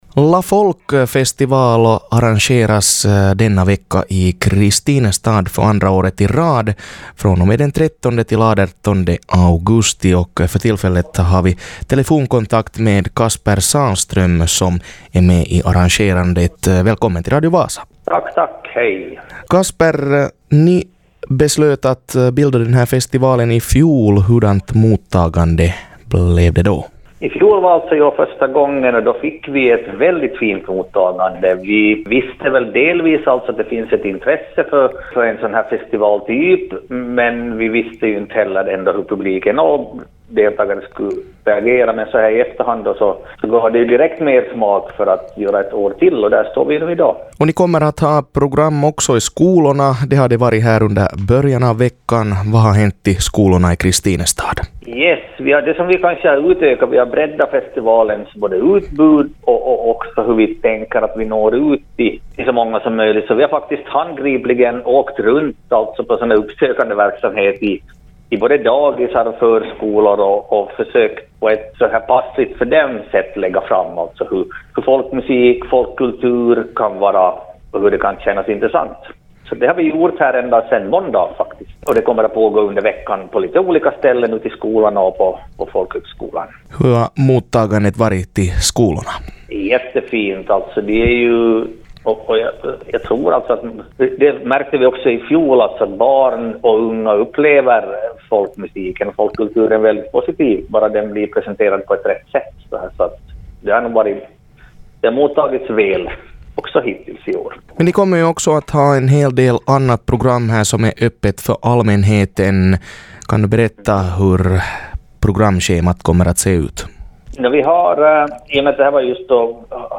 intervjuas